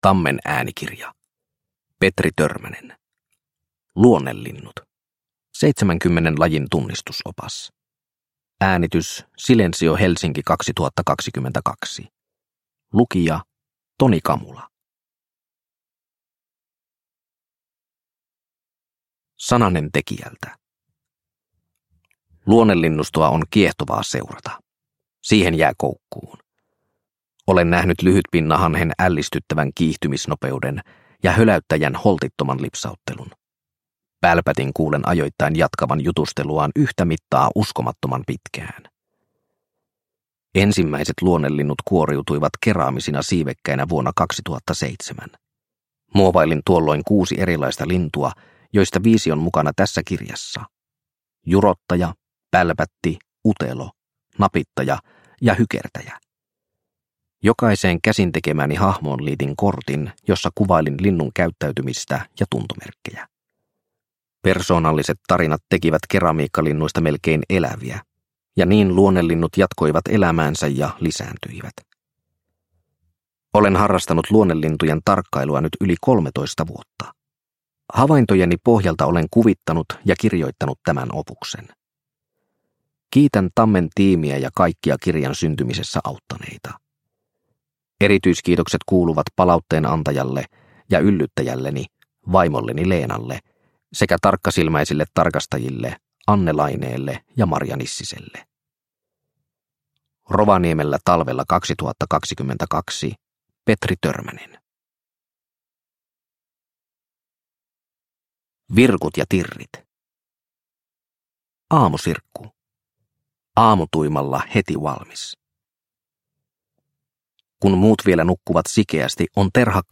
Luonnelinnut – Ljudbok – Laddas ner